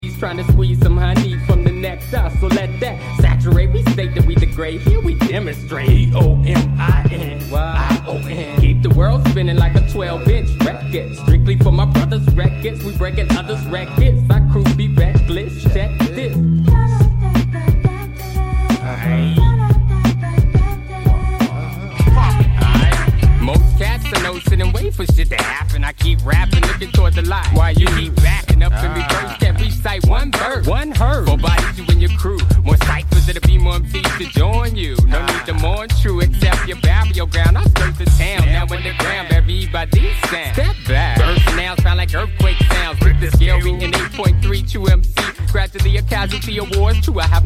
conscious/jazzy hip-hop